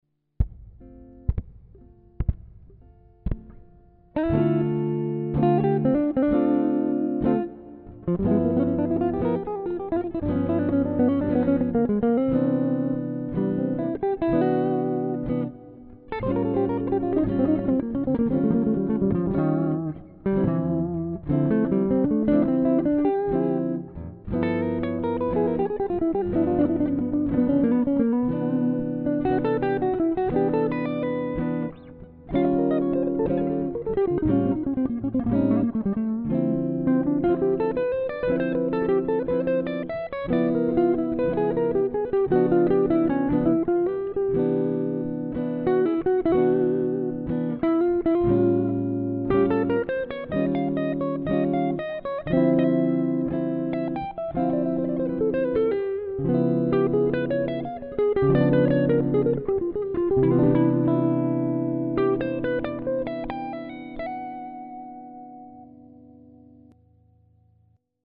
je m'étais amusé , sur un plan de sonothèque à jouer ceci très cool:
la grille : || : Dm7 | G7 | C7M | A7.5# : || II V I VI7
le mineur mélodique n'est pas utilisé sur G7 mais bien sur A7.